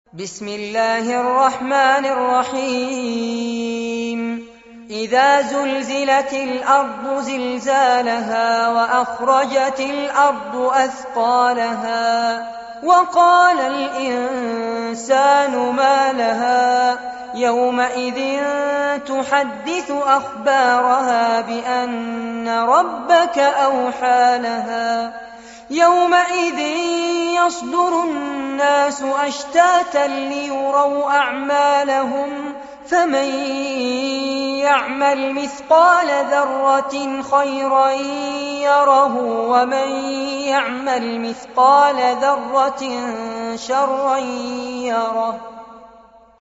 القرآن الكريم وعلومه     التجويد و أحكام التلاوة وشروح المتون
سورة الزلزلة- المصحف المرتل كاملاً
جودة عالية